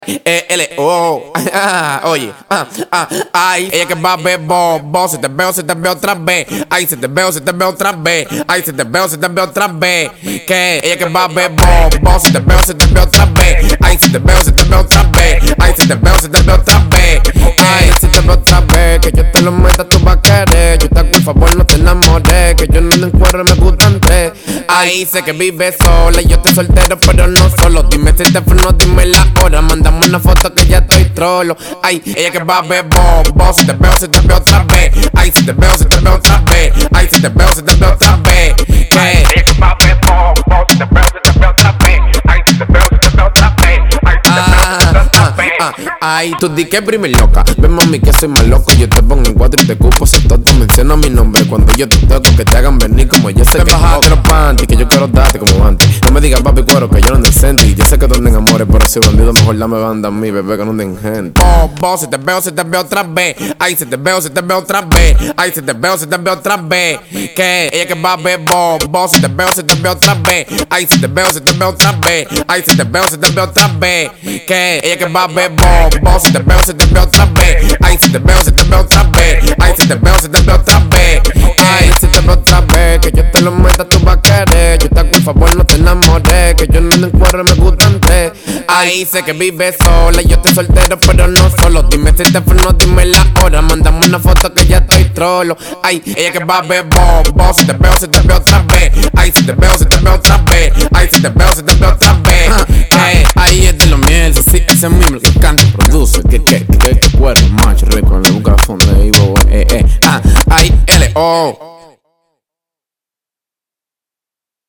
Genre: Dembow.